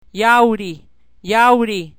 Pseudovowels w